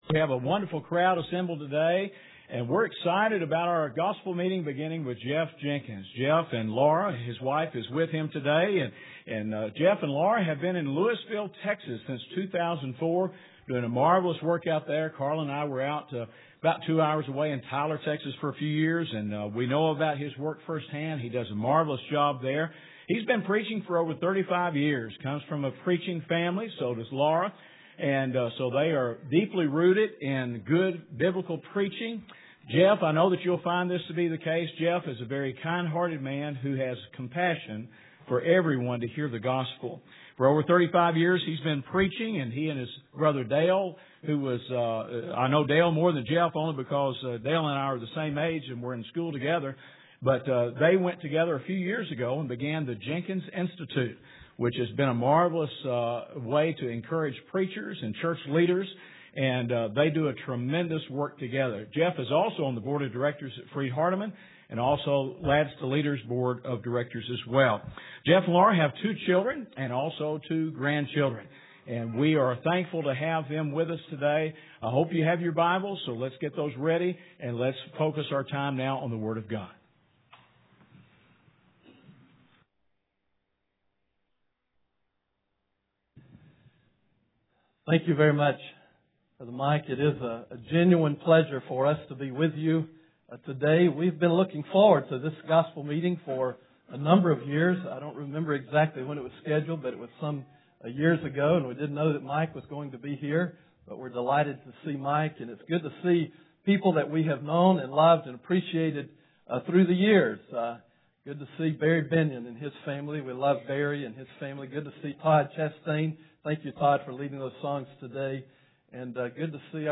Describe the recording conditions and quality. Gospel Meeting